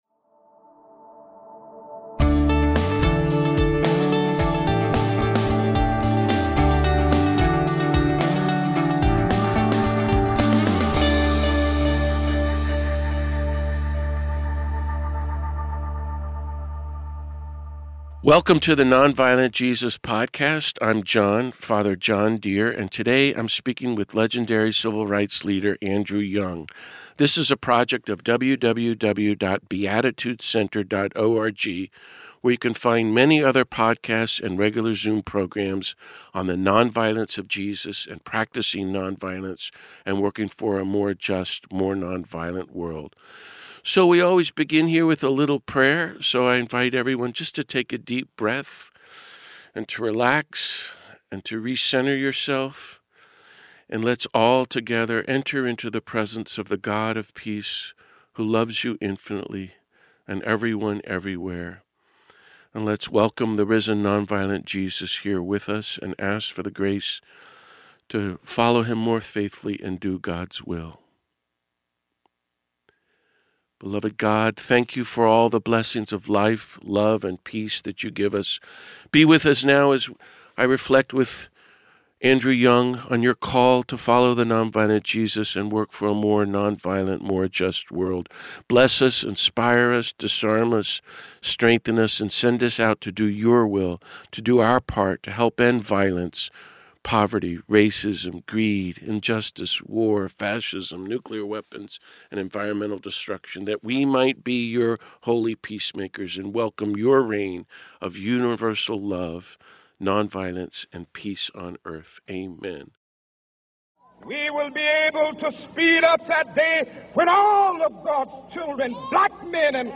This week I speak with legendary Civil Rights activist, author, pastor, politician, and diplomat Rev. Andrew Young to mark Dr. King's holiday.